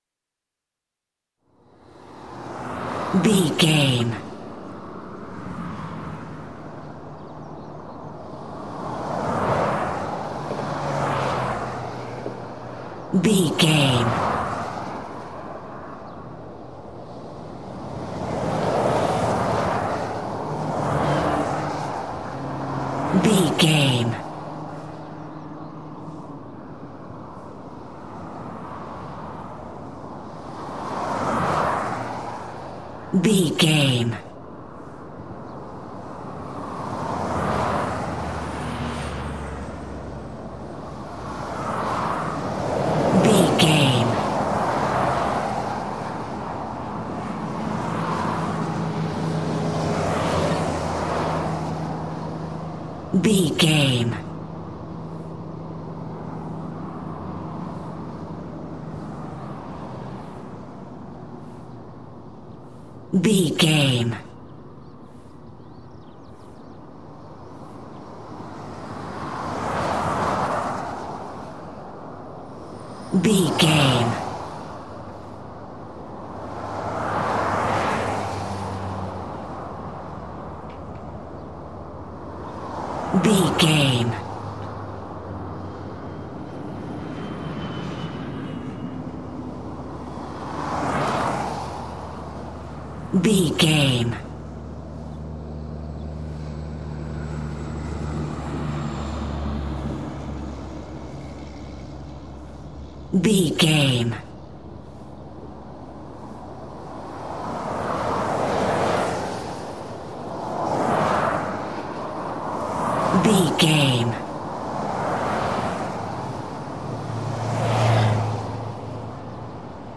City small avenue vehicles pass by
Sound Effects
urban
ambience